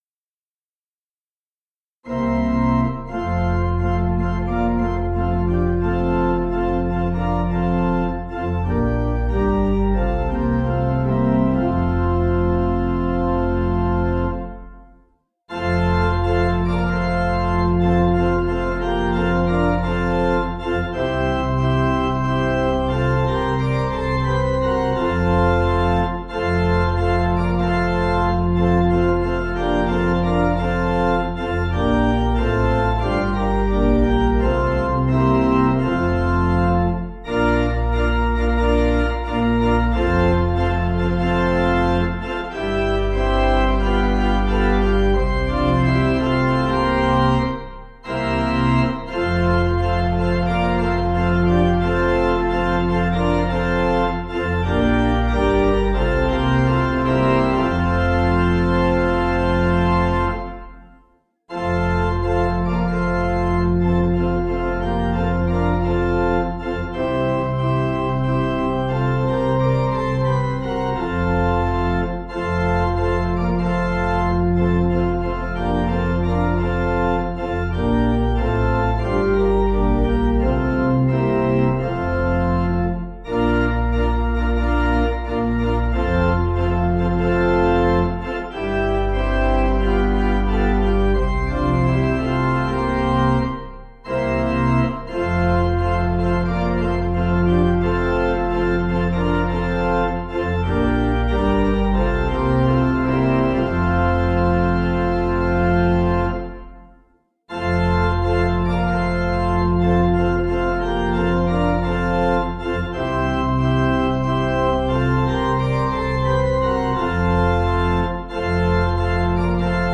Key: F Major